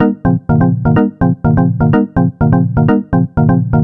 cch_synth_johnson_125_Ab.wav